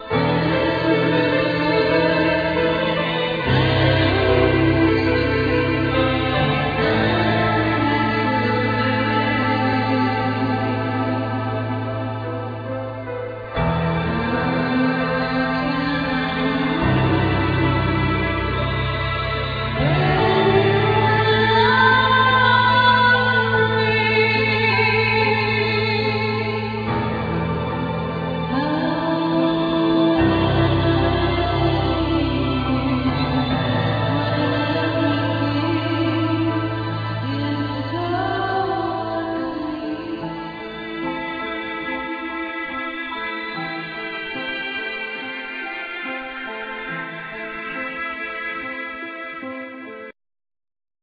Disc 1(Vocal Tracks)
Vocals,Viola
Flute
Cello
Violin
Electronics